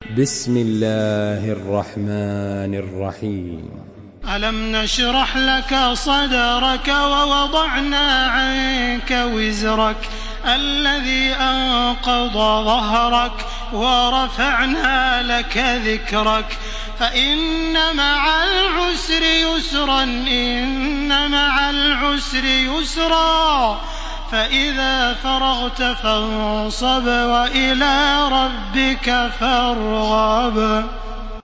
تحميل سورة الشرح بصوت تراويح الحرم المكي 1434
مرتل حفص عن عاصم